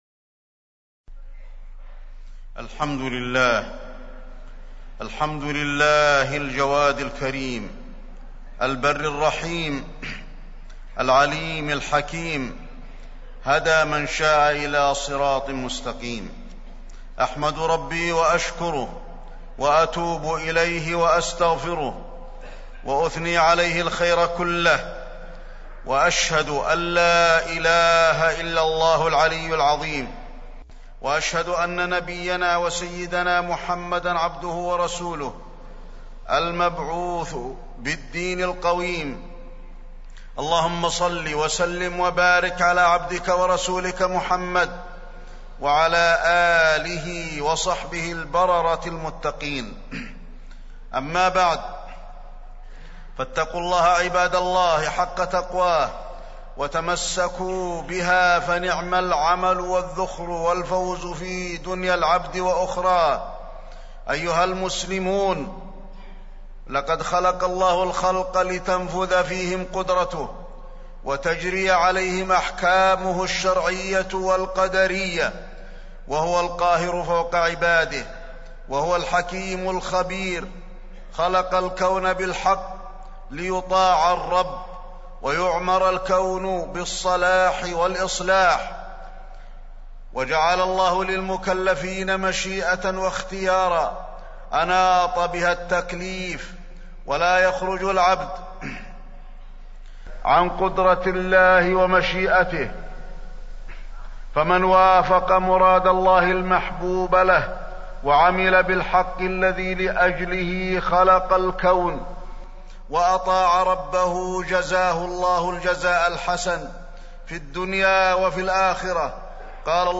تاريخ النشر ١٧ جمادى الأولى ١٤٢٦ هـ المكان: المسجد النبوي الشيخ: فضيلة الشيخ د. علي بن عبدالرحمن الحذيفي فضيلة الشيخ د. علي بن عبدالرحمن الحذيفي الجنة والنار